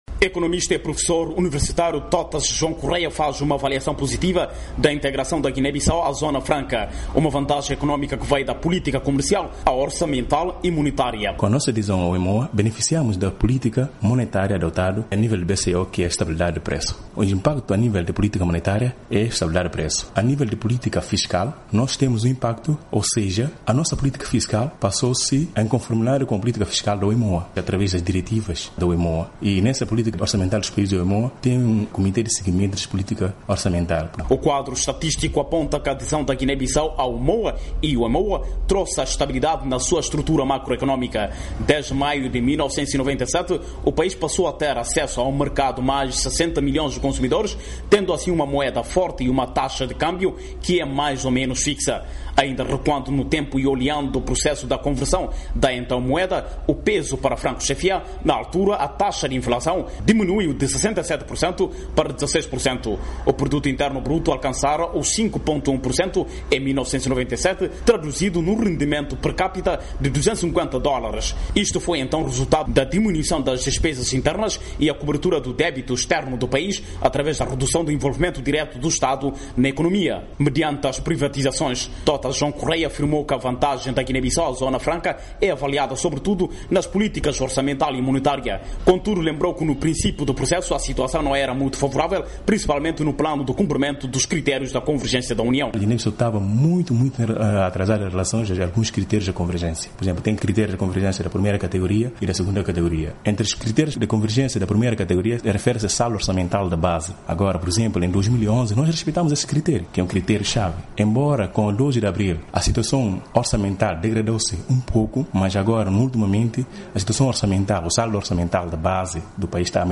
Na semana em que se assinala a data da integração, a VOA falou com cidadãos guineenses e técnicos versados na matéria.